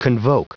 Prononciation du mot convoke en anglais (fichier audio)
Prononciation du mot : convoke